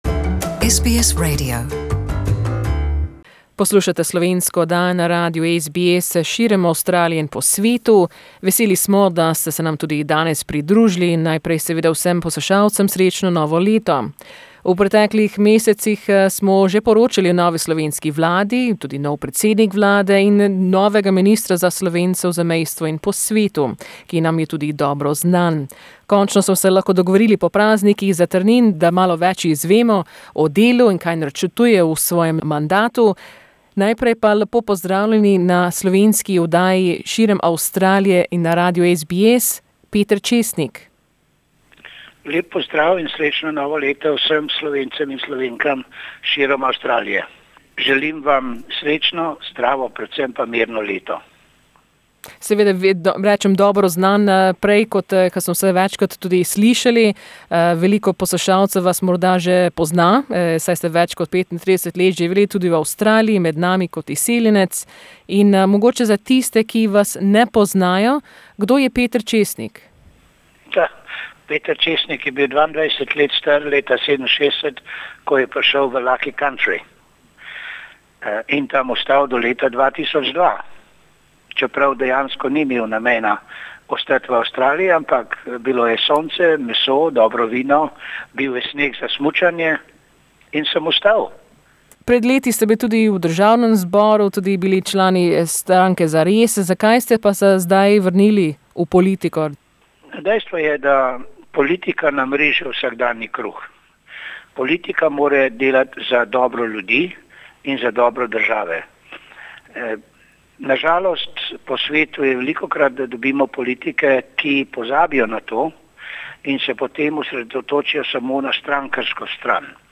We spoke with the new Minister of the Republic of Slovenia for Slovenians in neighbouring countries and abroad Peter Česnik. He explained more about his plans and goals for his term, as well as what elements he and his team will focus on in reviewing how they can help over half a million Slovenians who live outside Slovenia.